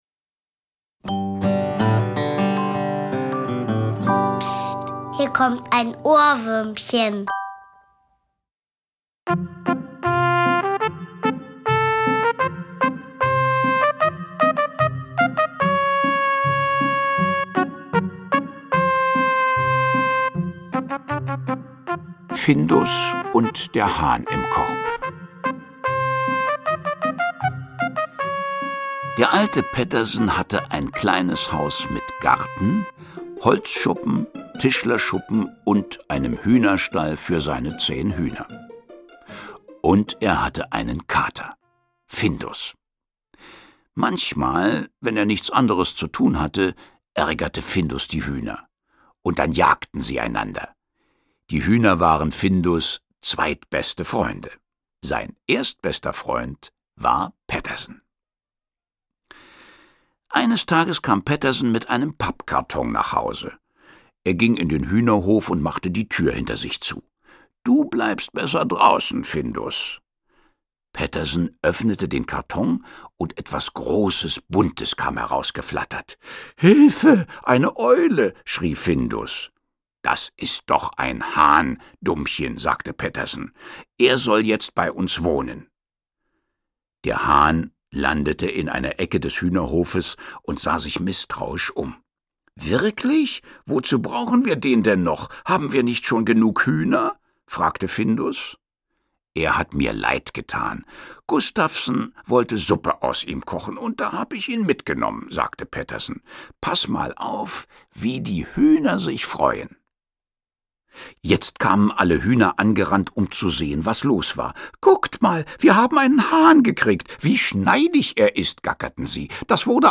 Hörbuch: Pettersson und Findus.